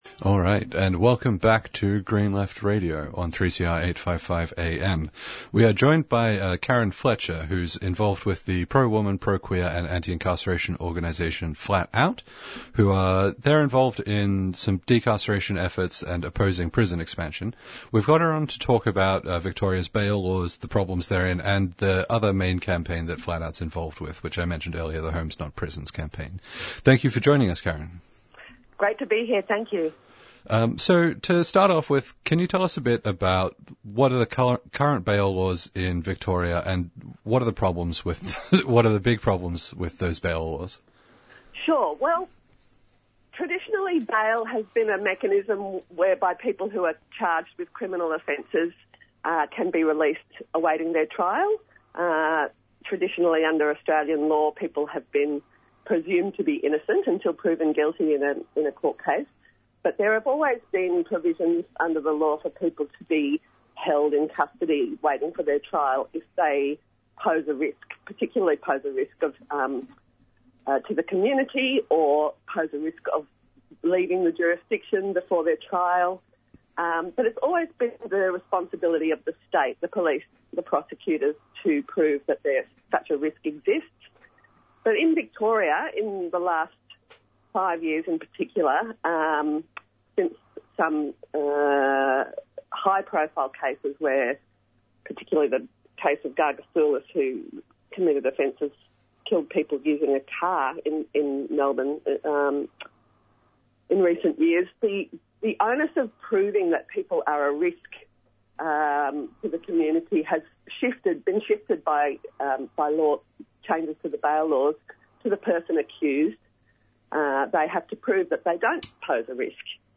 Discussion of Green Left article Blockade Australia protesters defy police crackdown from the presenters as part of a headline news discussion.